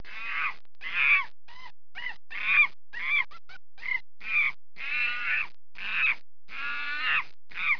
دانلود صدای حیوانات جنگلی 60 از ساعد نیوز با لینک مستقیم و کیفیت بالا
جلوه های صوتی